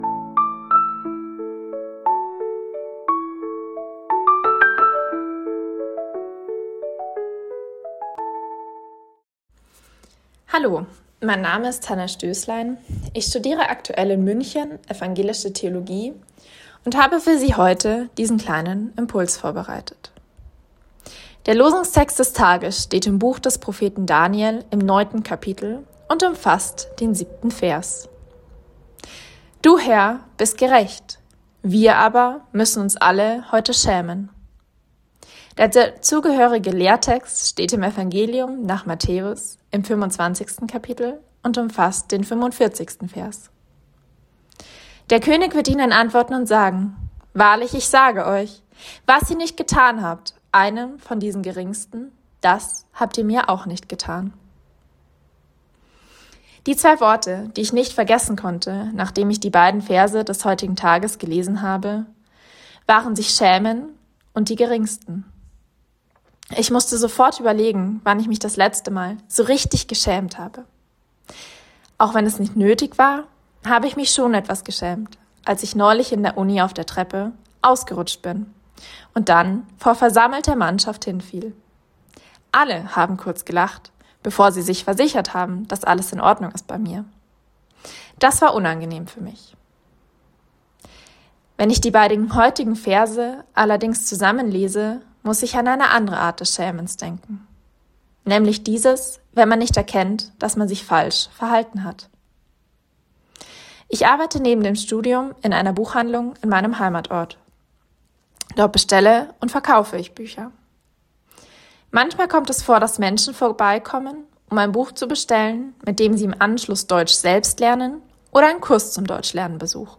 Losungsandacht für Freitag, 06.02.2026 – Prot.